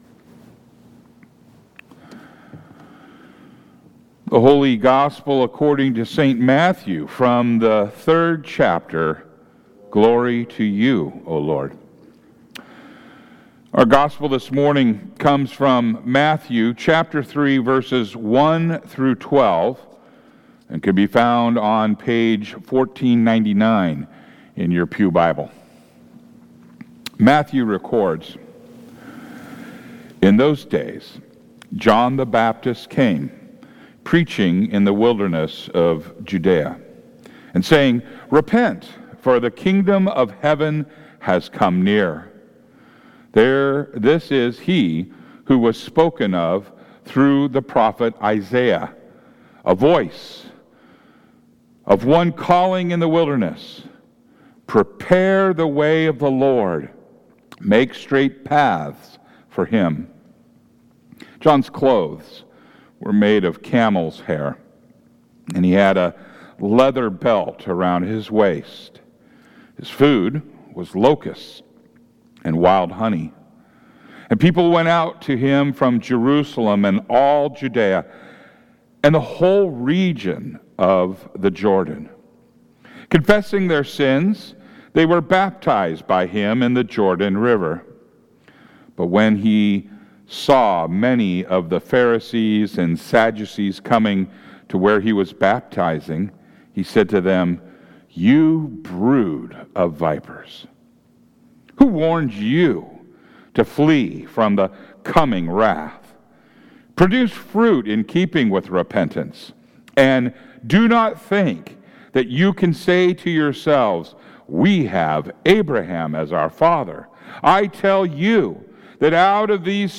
Worship Services